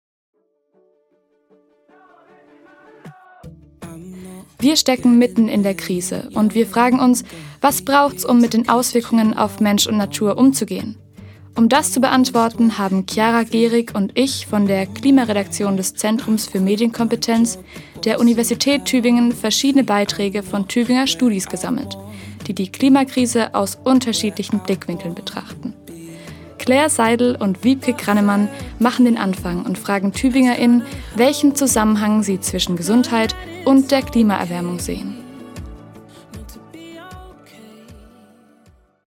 Radio Micro-Europa: Sendung (550) „Klimakrise – Was braucht’s?“ am Montag, den 5. August 2024 von 15 bis 16 Uhr im Freien Radio Wüste Welle 96,6.